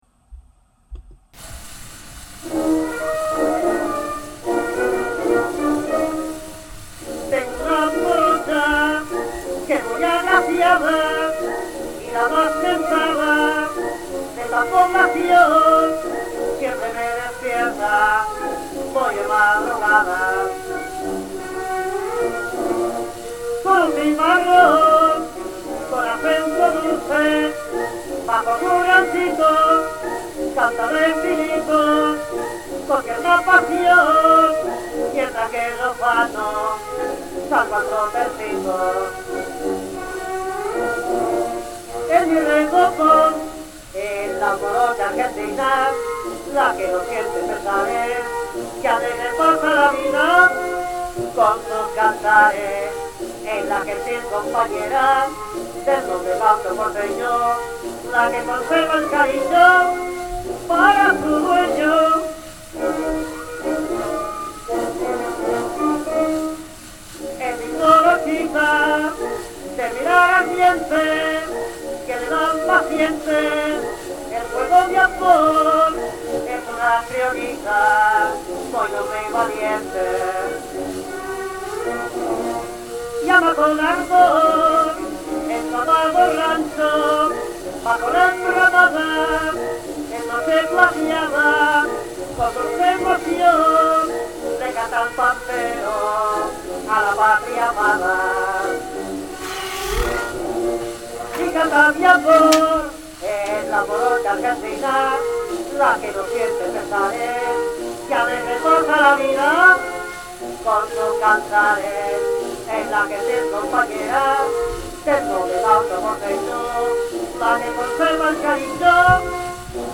Genre tango